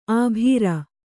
♪ abhīra